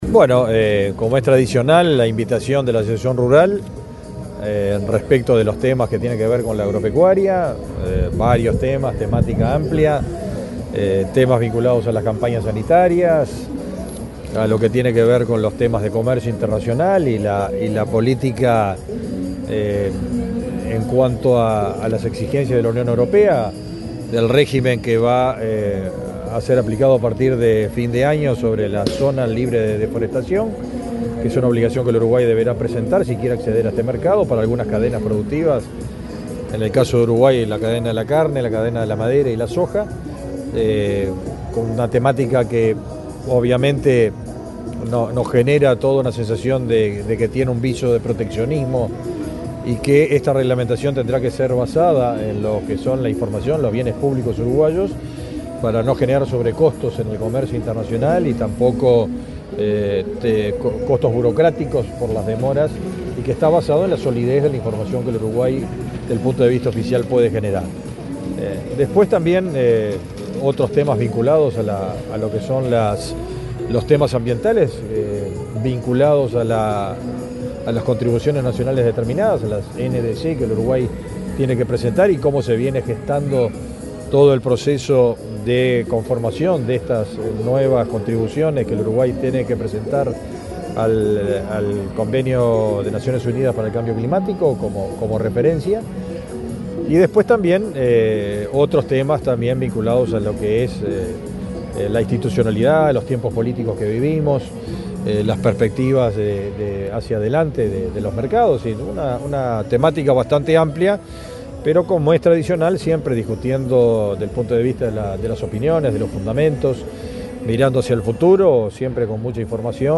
Declaraciones del ministro de Ganadería, Fernando Mattos
El ministro de Ganadería, Fernando Mattos, dialogó con la prensa, luego de reunirse con directivos de la Asociación Rural del Uruguay en la Expo Prado